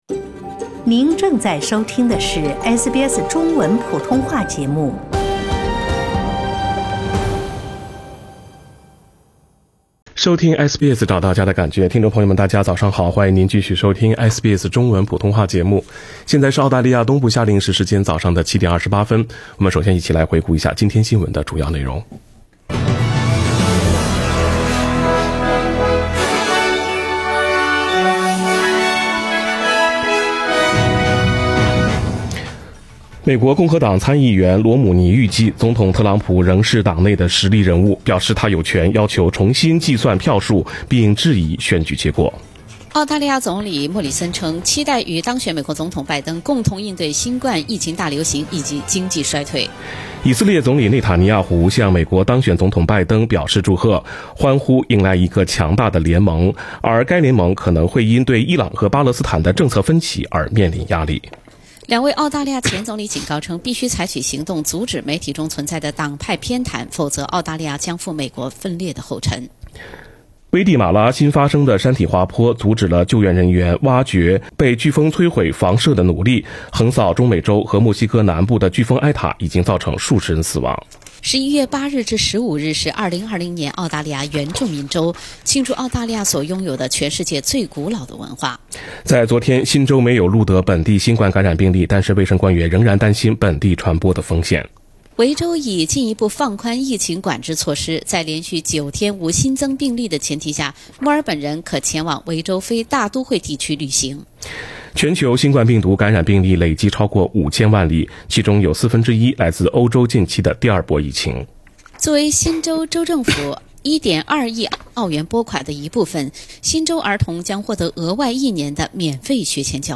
SBS早新闻（11月09日）